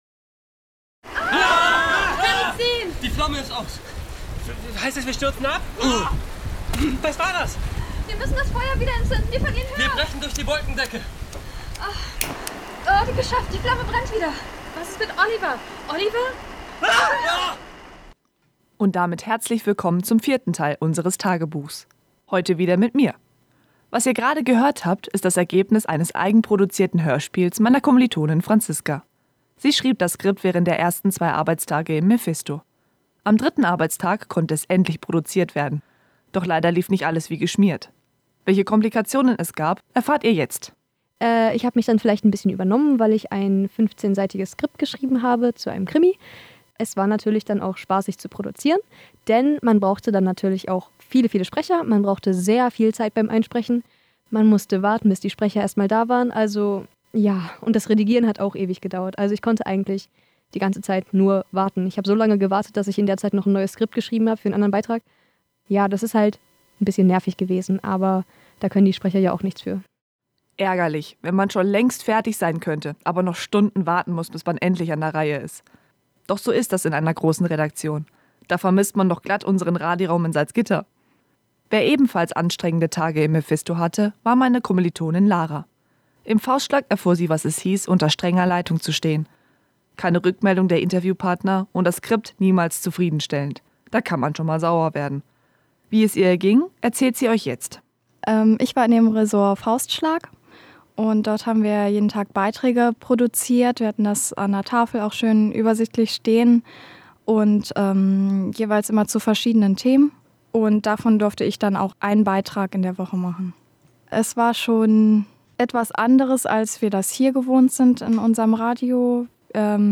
Audiotagebuch – Eintrag Vier
In unserem vierten Tagebucheintrag berichten sie, was ihnen passiert ist.